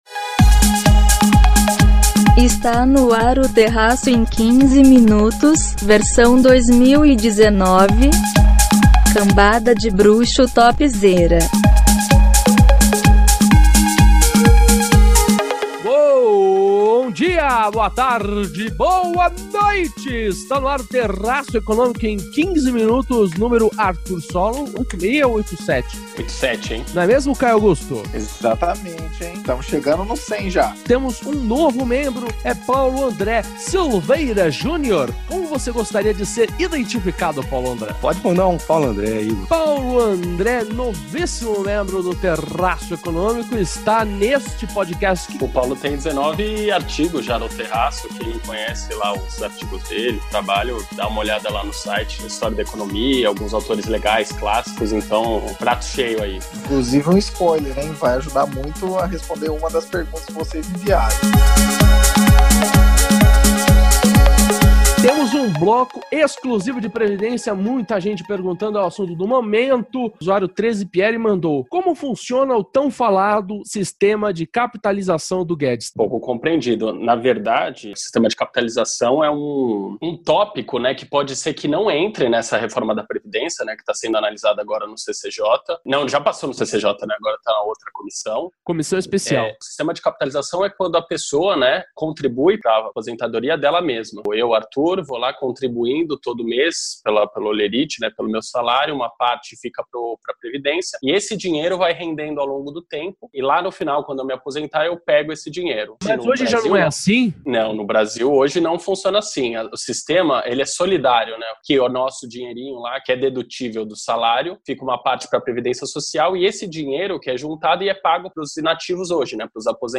respondendo às perguntas dos seguidores do Instagram: